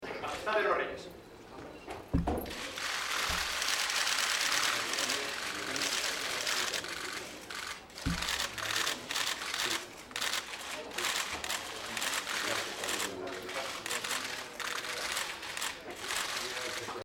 Y arreció una lluvia de flashes y sonidos difícil de repetir (archivo MP3).